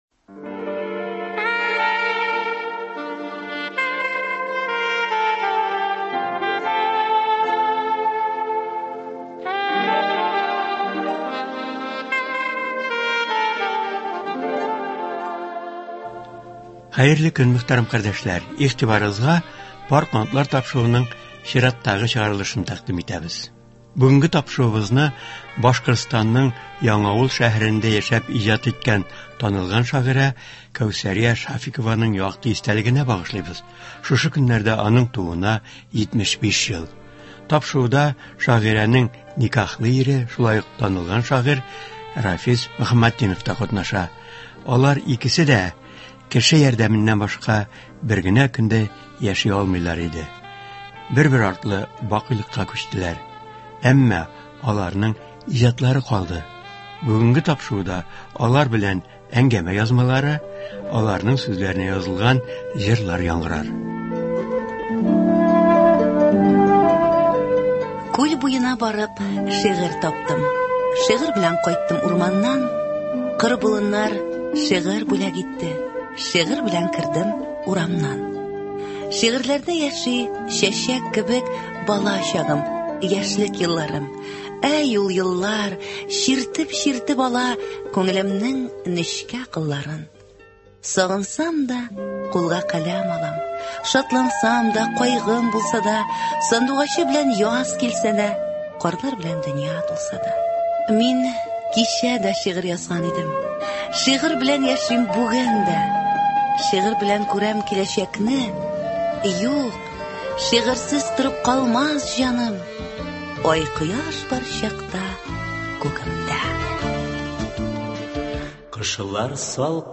Тапшыруда алар белән әңгәмә язмалары, аларның сүзләренә язылган җырлар яңгырар.